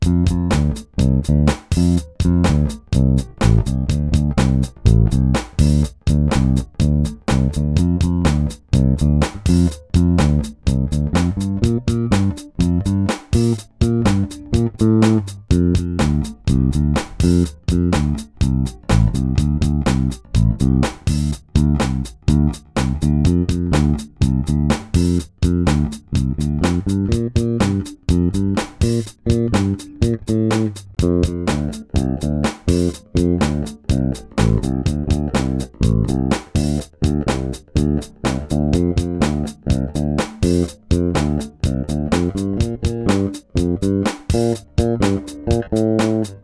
- no active electronics The fingerboard may be broader than modern sixstringers', but this bass delivers a round, pronounced, well placed sound that works well in all styles of music.
peavey_tl6.wav